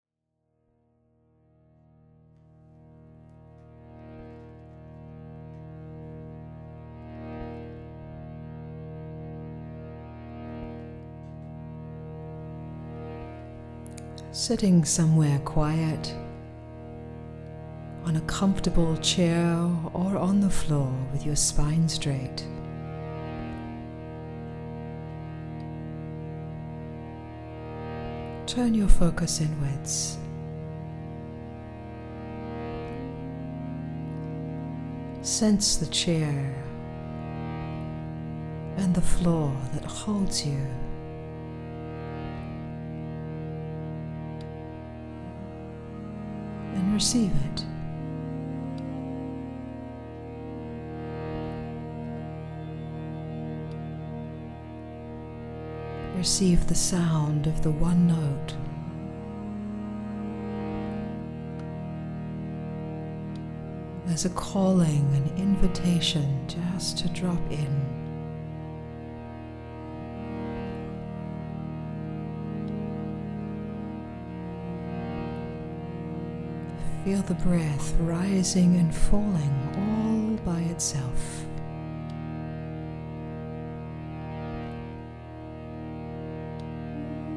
Audio Meditations
Tamboura & Bells